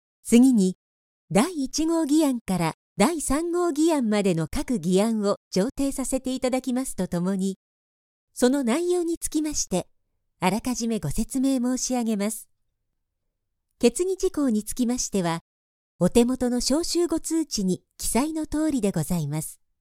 テレビ・ラジオ、CM、企業VP、ゲーム、施設内放送など、さまざまなジャンルで多くの実績があり、明るく爽やかなもの、堅く落ち着いたもの、優しい語りまで、幅広く対応可能です。
– ナレーション –
female109_25.mp3